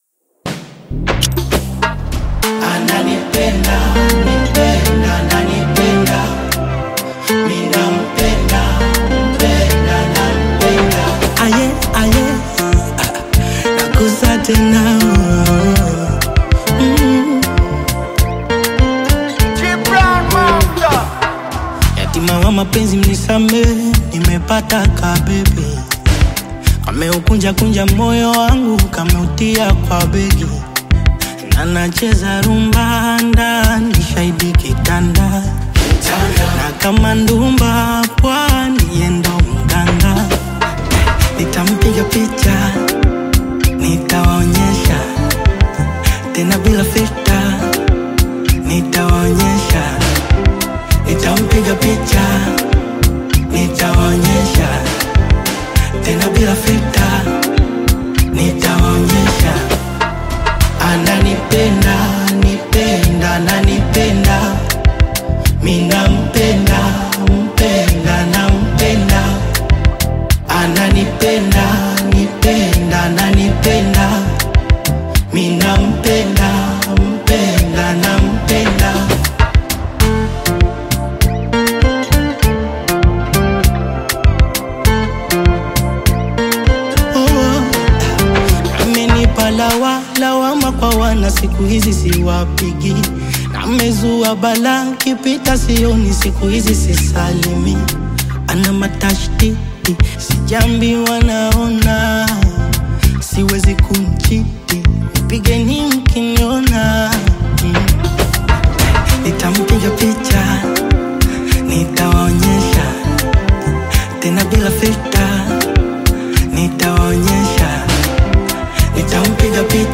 Tanzanian singer and songwriter
This heartfelt song dives into themes of love